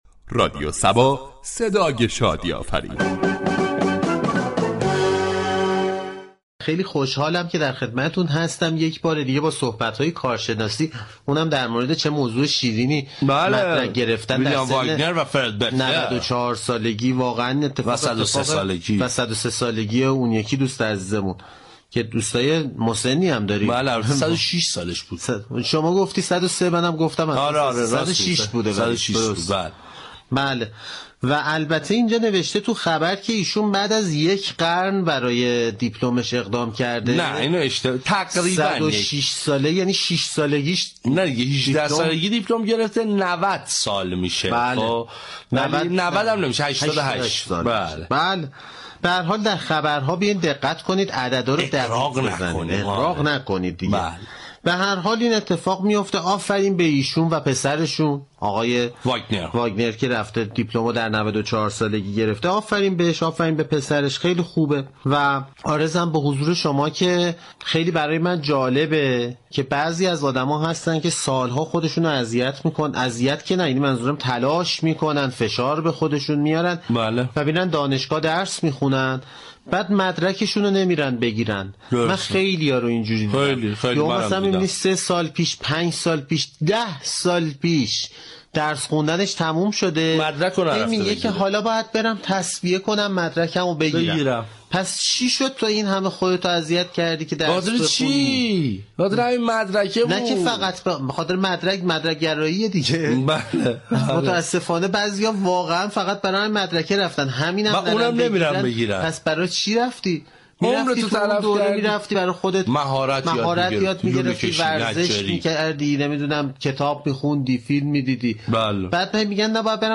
در این برنامه با نگاهی طنز در بخش های مختلف برنامه «دیباچه» ، «به ما چه» ، «دیوانچه» و «یعنی چه» به كارشناسی و چرایی انتخاب این خبر می پردازد .